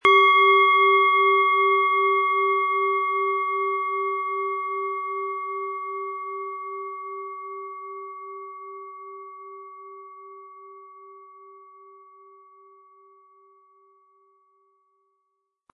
Tibetische Universal-Klangschale, Ø 12,3 cm, 260-320 Gramm, mit Klöppel
Wir haben die Klangschale beim Aufnehmen angespielt und den subjektiven Eindruck, dass sie sämtliche Körperregionen gleich deutlich anspricht.
Um den Originalton der Schale anzuhören, gehen Sie bitte zu unserer Klangaufnahme unter dem Produktbild.
Aber uns würde der kraftvolle Klang und diese außerordentliche Klangschwingung der überlieferten Fertigung fehlen.